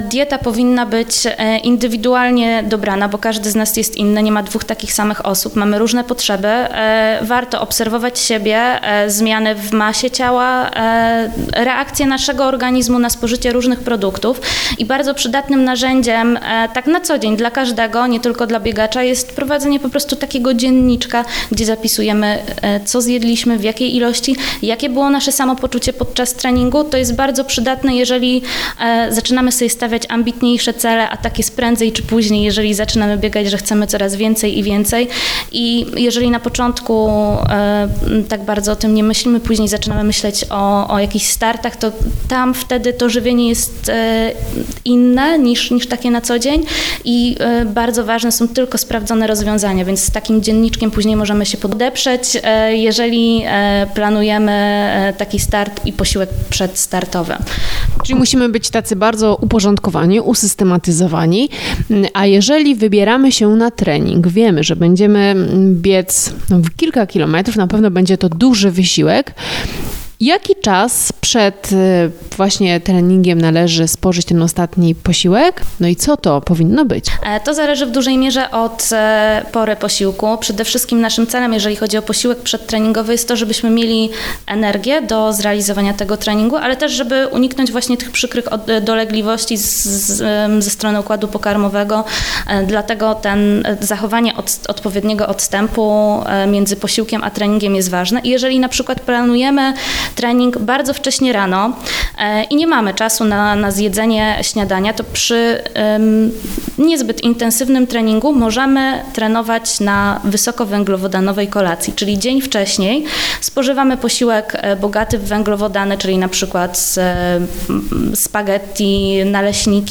w rozmowie z dietetyczką